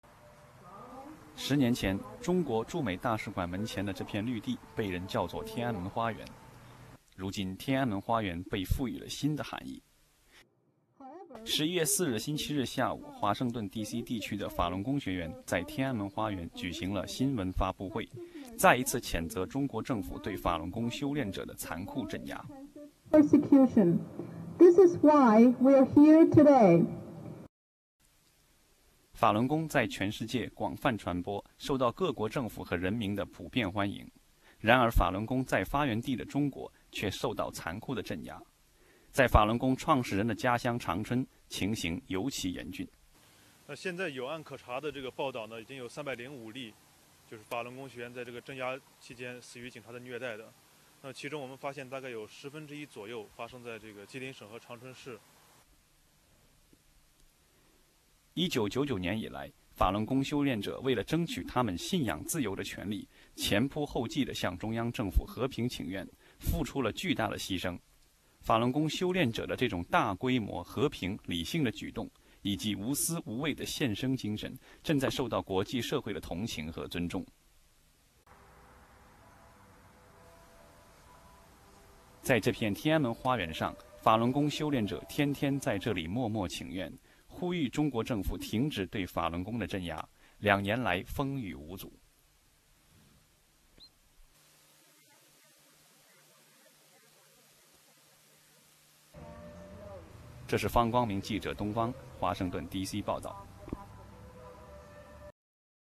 DC_sunday_news_256k.ra